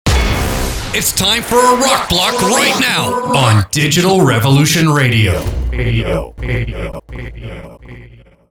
Running typically between ten and sixty seconds, promos combine voiceover, music, and effects to create anticipation and drive listener action.